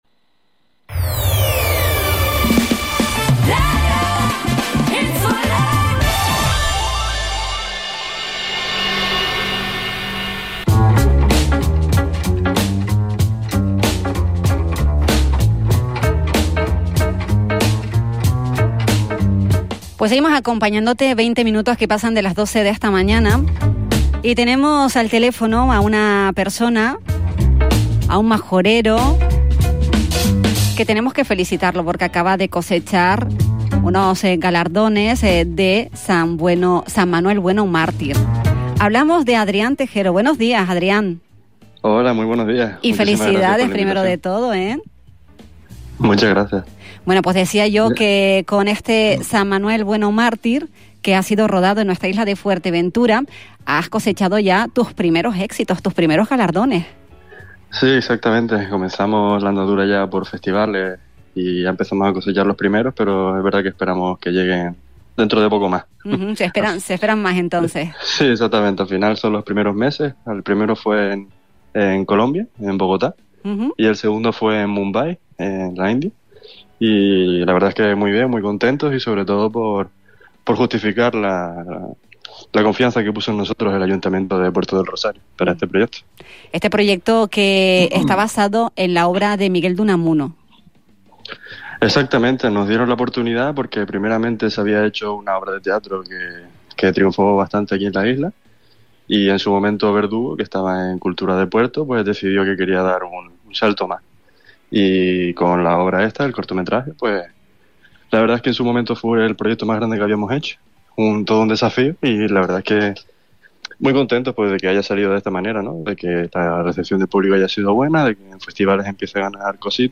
Inspirado en la obra literaria de Miguel de Unamuno, el cortometraje ha impresionado por su calidad artística y su conexión con los paisajes de la isla. En una entrevista para La Mañana Xtra de Radio Insular